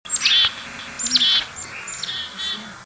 What animal makes this sound?